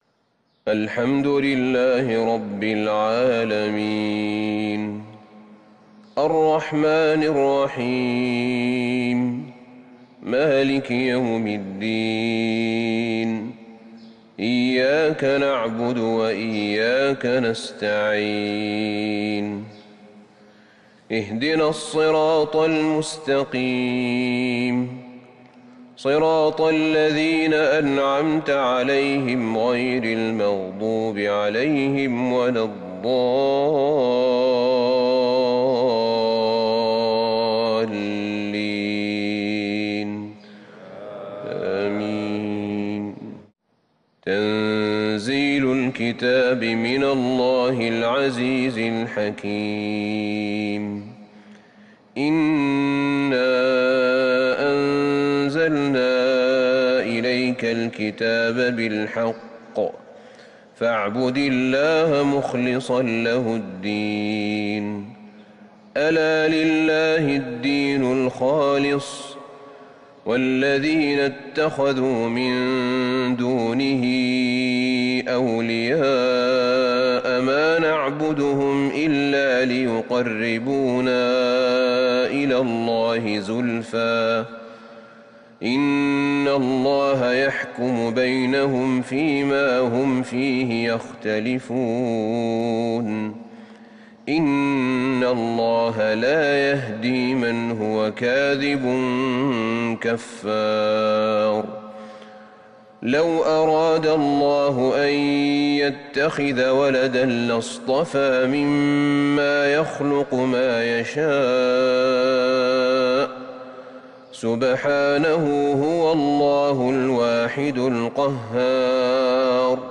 فجر الأحد 7-4-1442هـ من سورة الزمر | Fajr prayer from Surat AzZumar |22/11/2020 > 1442 🕌 > الفروض - تلاوات الحرمين